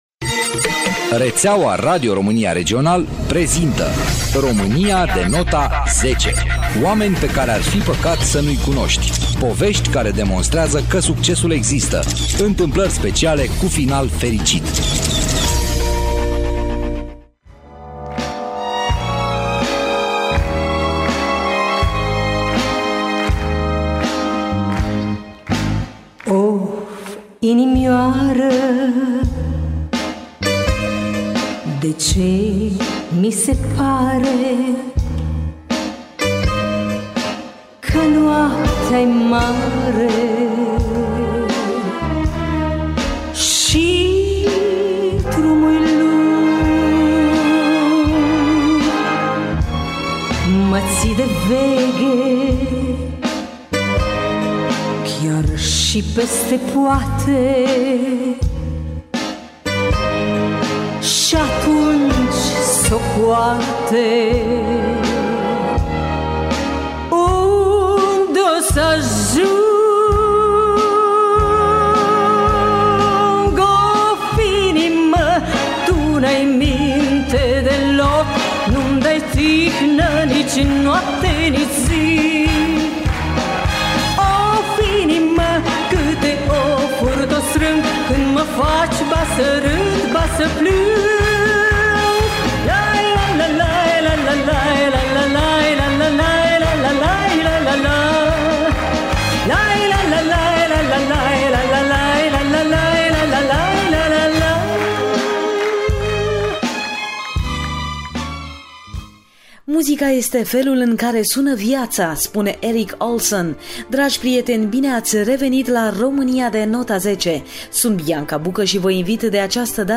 Renumitul artist și-a deschis o poartă a sufletului, în exclusivitate, pentru Radio România Brașov FM și a lăsat la vedere cateva Do,Re, Mi-uri ale vieții sale, într-un interviu mai mult decât special, acordat cu doar câteva ore înainte de a urca pe scenă.